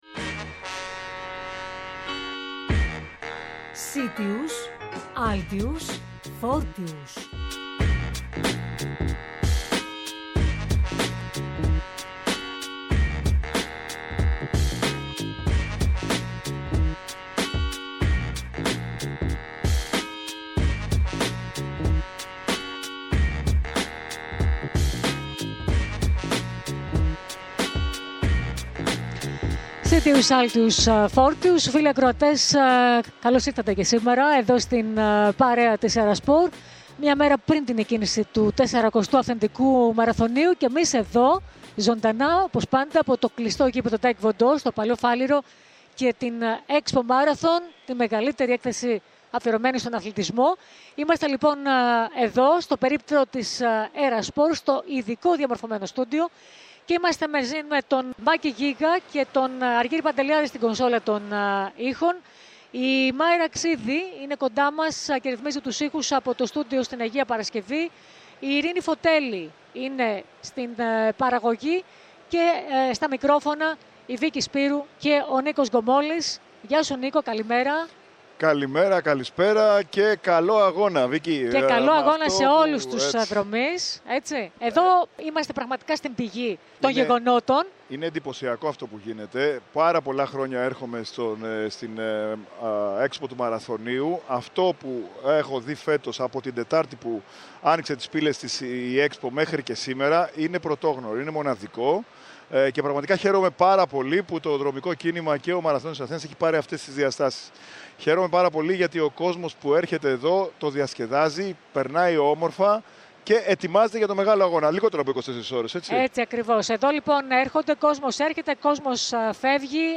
Μέσα από την EXPO και το ειδικό διαμορφωμένο στούντιο της ΕΡΑΣΠΟΡ, φιλοξενήσαμε σήμερα, παραμονή του μαραθωνίου, σπουδαίους καλεσμένους!!